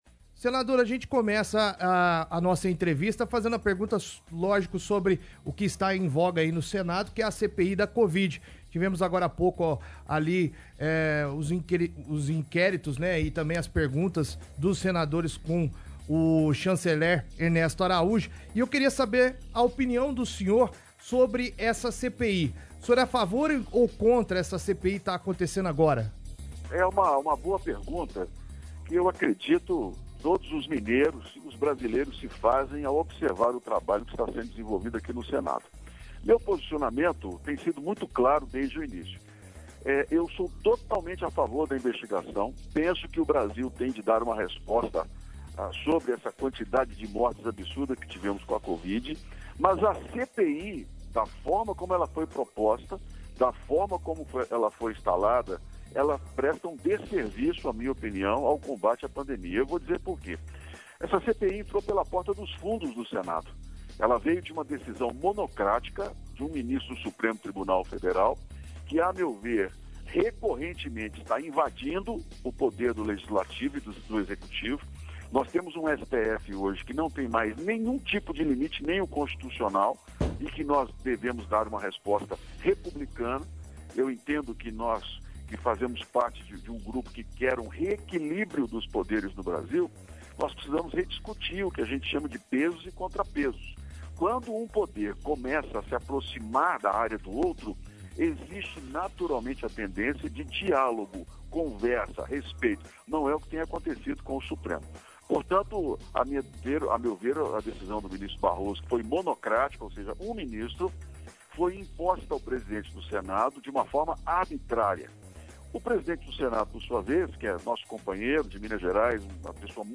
BRASIL | Senador Carlos Viana (PSD-MG) fala com equipe da Onda Poços – ONDA POÇOS